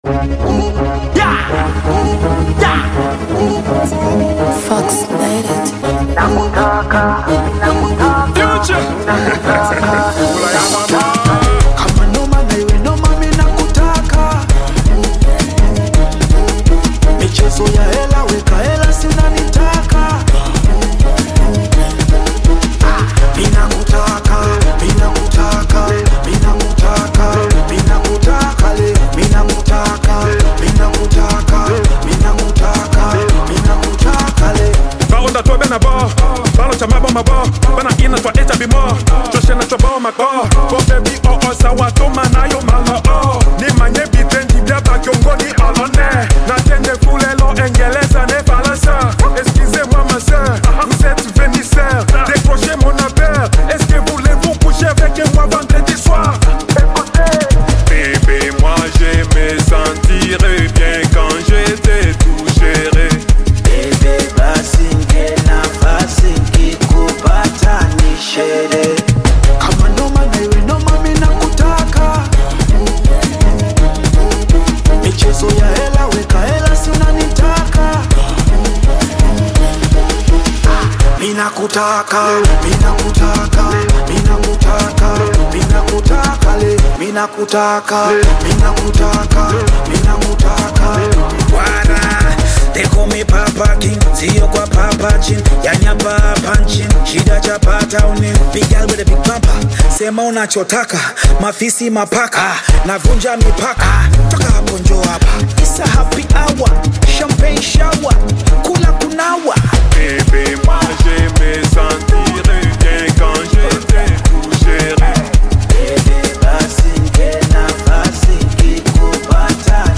smooth and catchy